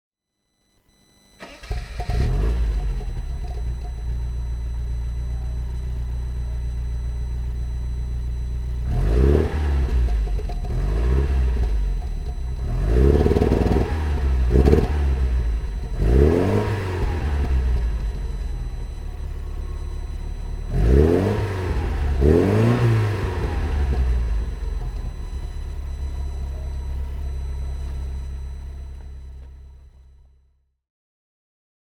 Jensen GT (1976) - Starten und Leerlauf